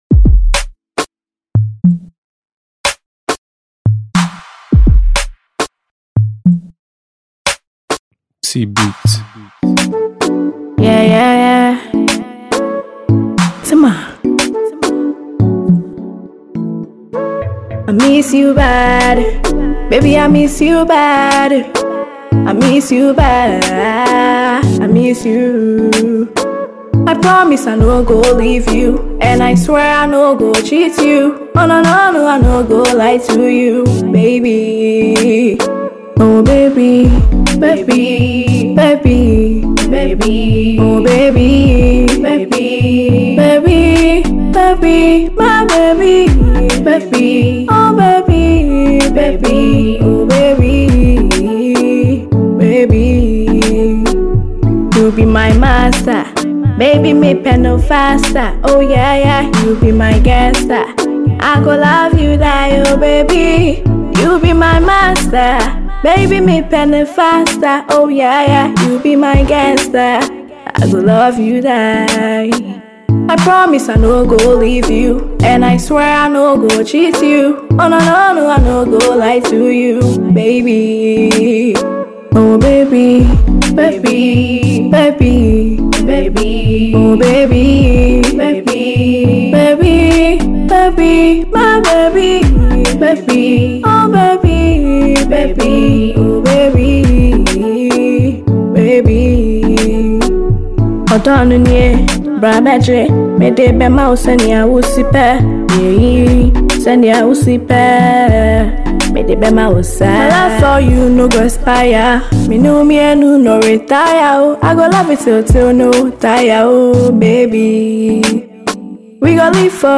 Afro pop songstress
A melodious song